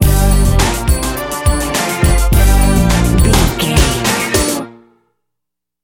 Uplifting
Aeolian/Minor
Fast
drum machine
synthesiser
electric piano